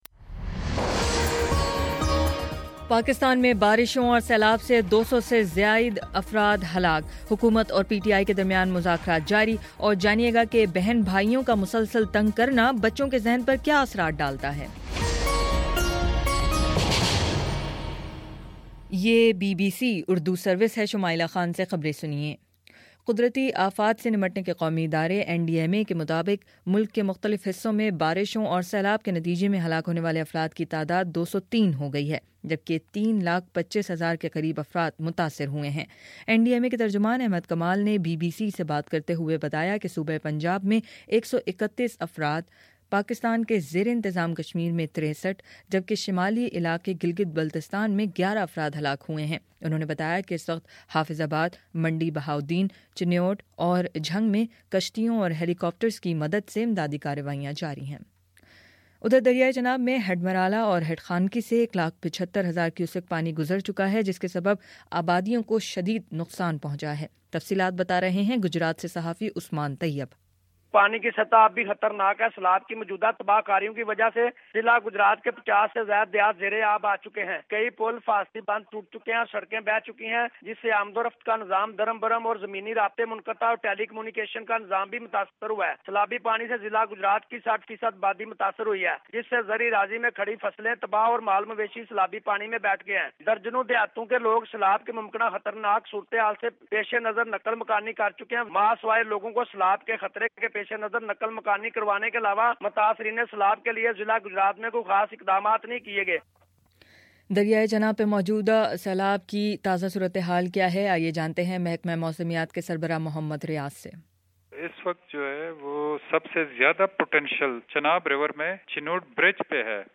08 ستمبر: شام چھ بجے کا نیوز بُلیٹن